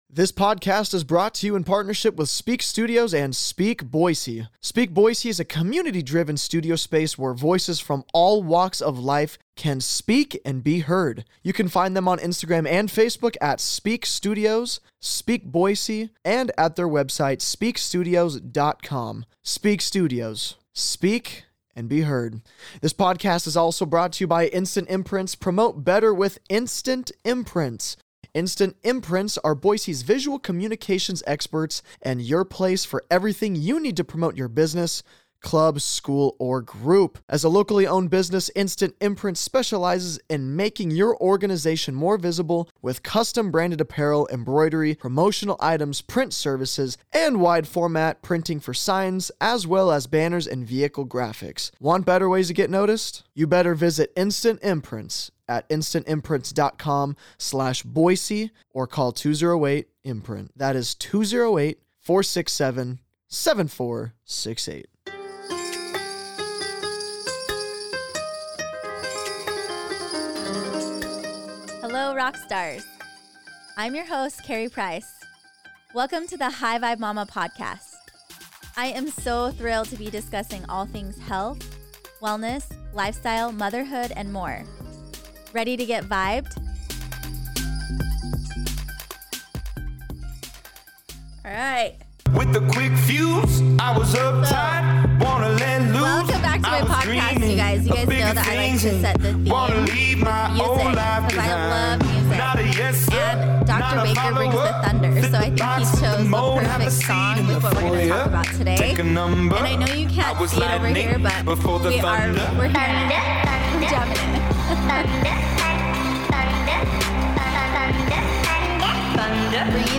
#5 CHIROPRACTIC & THE IMMUNE SYSTEM, INTERVIEW